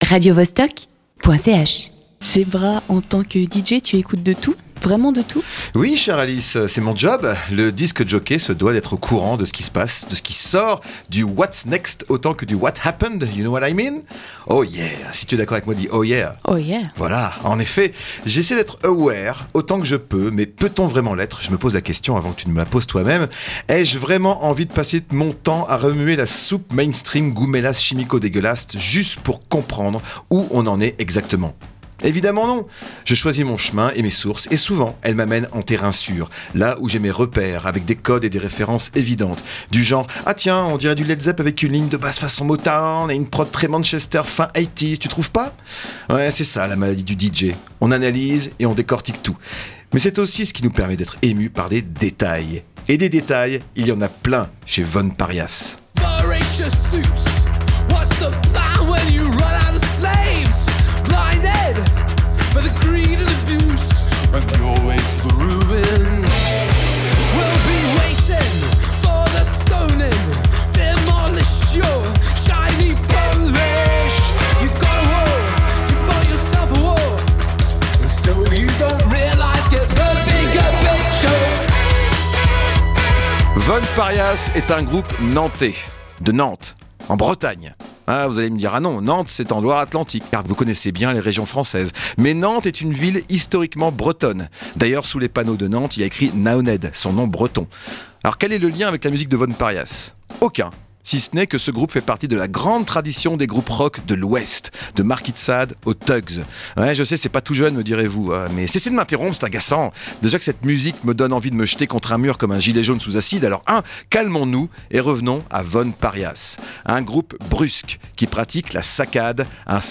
Von Pariahs est un groupe nantais, de Nantes, en Bretagne, et fait partie de la grande tradition des groupes rock de l’Ouest, de Marquis de Sade aux Thugs. Un groupe brusque qui pratique la saccade, un sport de combat musical à base de tensions explosives. La rythmique est sèche, et la basse est précise, le chant est aiguisé, les guitares attisent, les doigts dans la prise, on balise et soudain, bam! coup de crache, et pédale disto’, on s’en prend plein la gueule et on dit merci, car Von Pariahs est dans la partie.